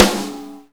soft-hitclap7.wav